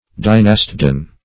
Search Result for " dynastidan" : The Collaborative International Dictionary of English v.0.48: Dynastidan \Dy*nas"ti*dan\, n. [Gr.